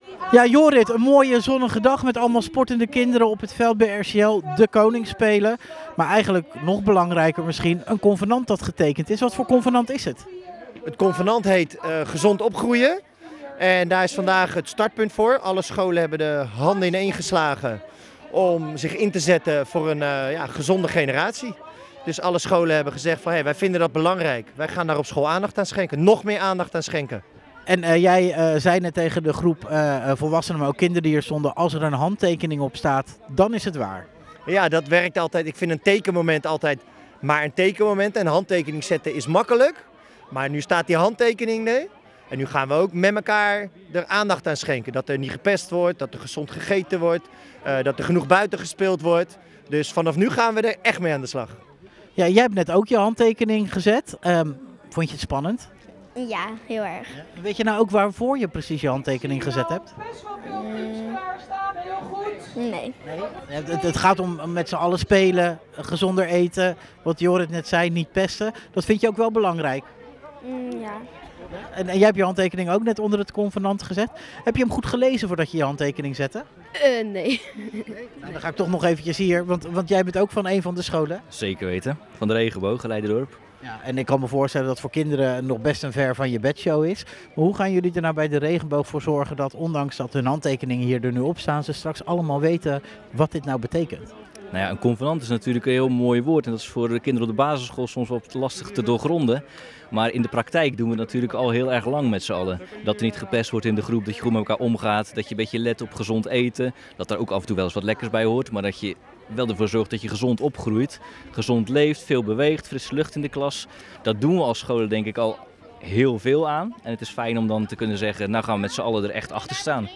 Een interview